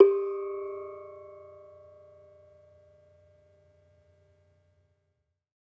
Bonang-G3-f.wav